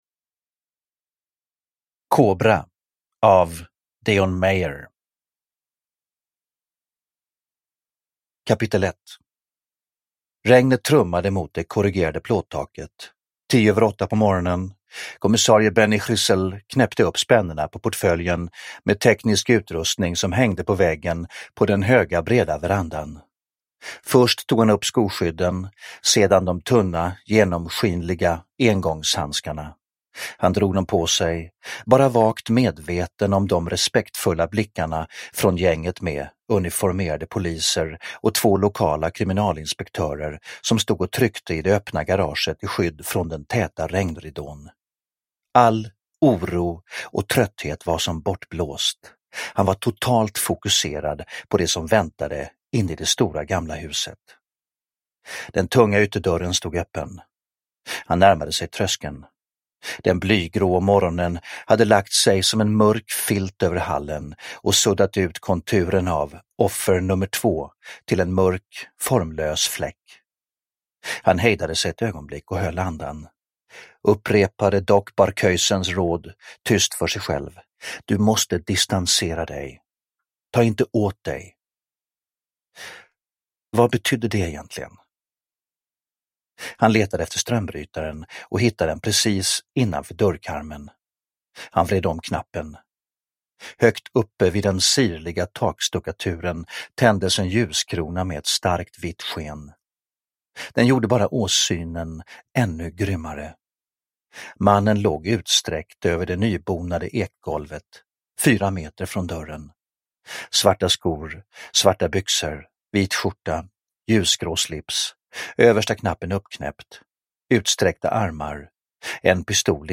Kobra – Ljudbok – Laddas ner
Uppläsare: Stefan Sauk